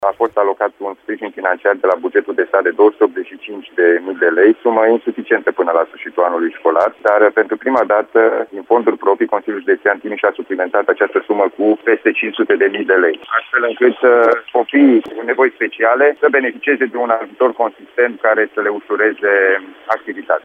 Anunțul a fost făcut la Radio Timișoara de președintele instituției, Alin Nica, iar măsura a fost luată după ce s-a constatat că suma repartizată de la București era insuficientă: